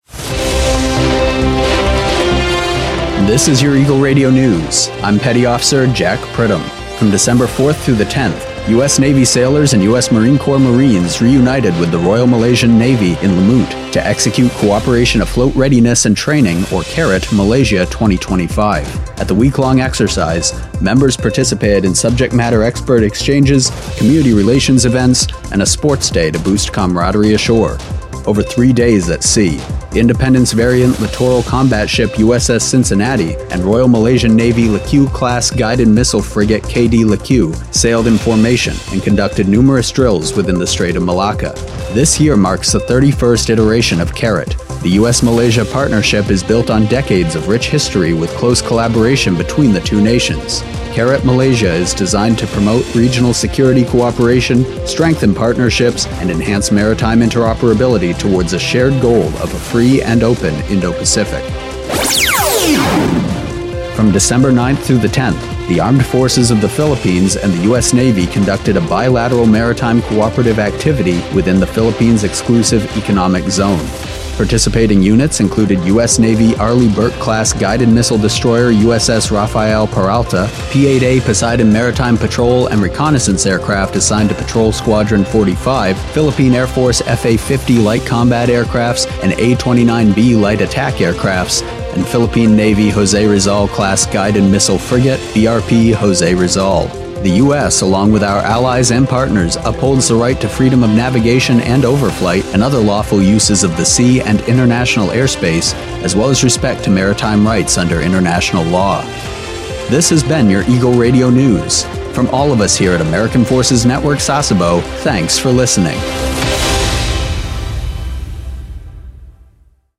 NEWSCAST 16 DEC 25: CARAT 2025 Malaysia & U.S. Navy, Philippines MCA